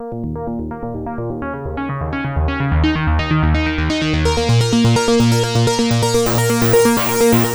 Juno Bliss Bb 127.wav